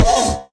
Minecraft Version Minecraft Version latest Latest Release | Latest Snapshot latest / assets / minecraft / sounds / mob / horse / skeleton / hit2.ogg Compare With Compare With Latest Release | Latest Snapshot